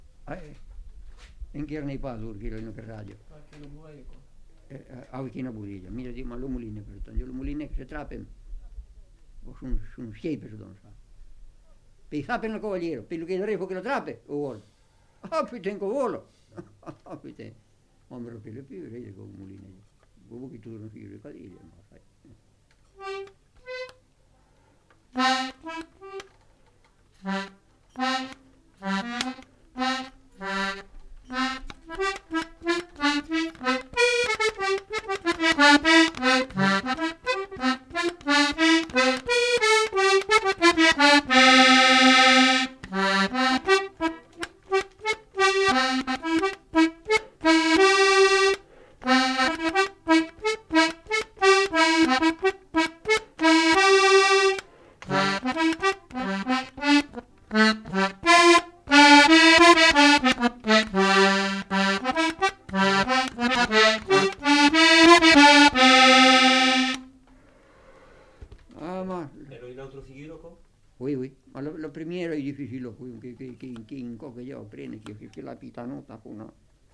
Genre : morceau instrumental
Instrument de musique : accordéon diatonique
Danse : quadrille
Ecouter-voir : archives sonores en ligne